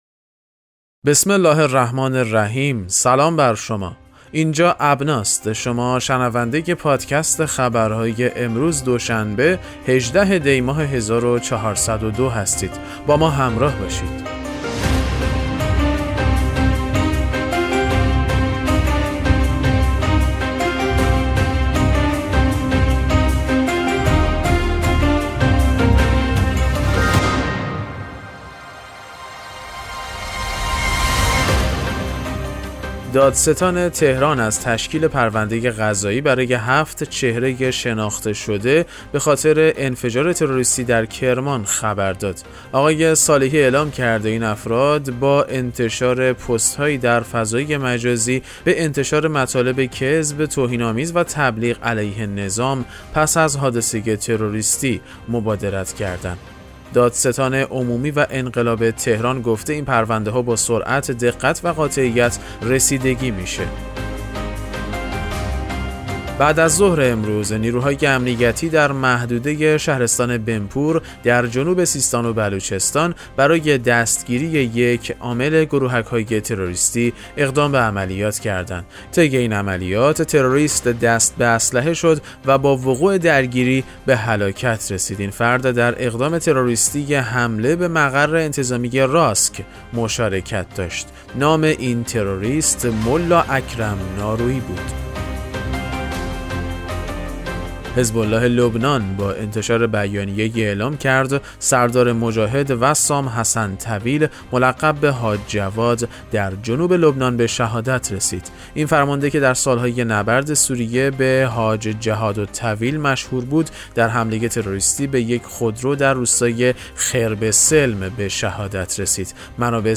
پادکست مهم‌ترین اخبار ابنا فارسی ــ 18 دی 1402